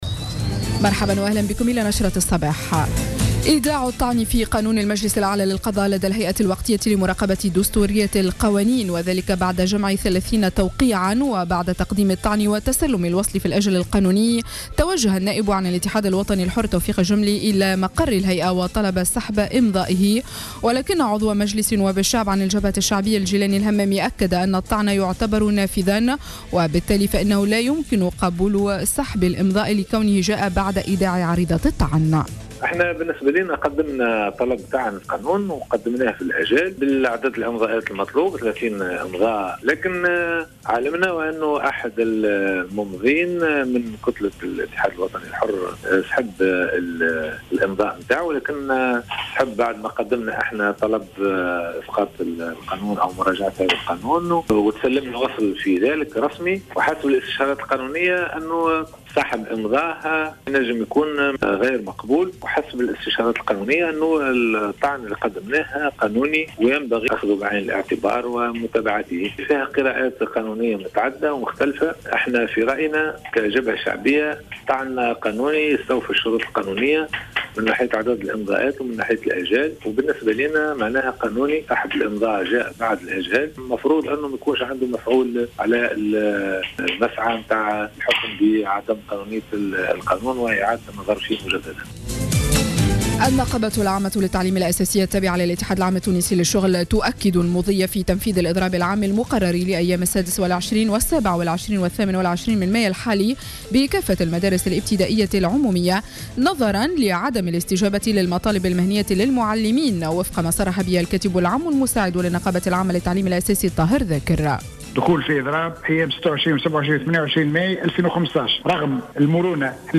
نشرة أخبار السابعة صباحا ليوم السبت 23 ماي 2015